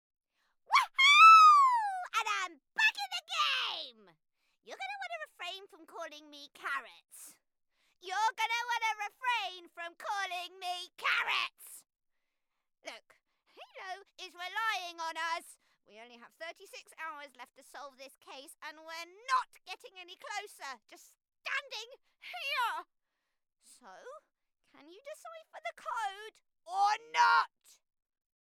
Voice Reel
Child Game Demo